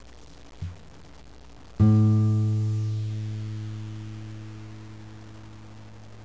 Tuning the Guitar
2. The next string down (the 5th string) should sound something like
string5.wav